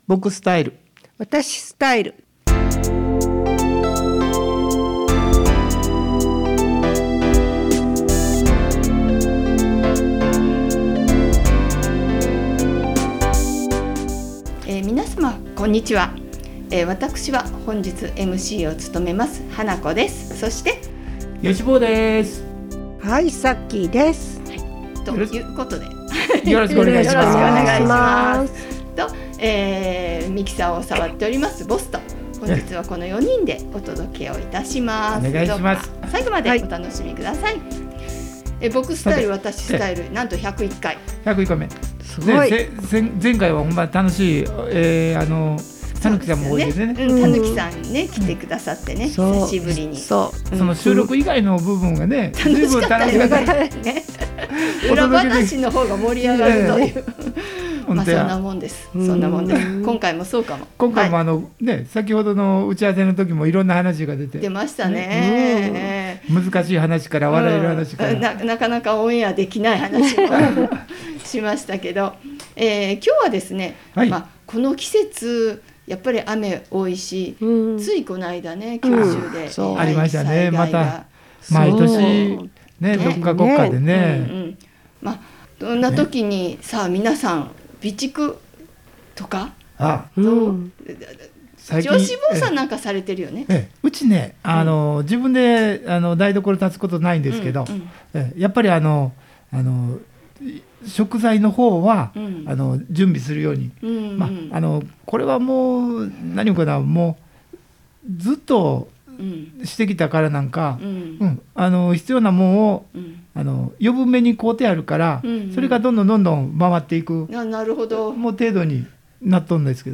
▼ワイガヤ・・・・・防災備蓄（ローリング ストック）を考える
場所：相生市総合福祉会館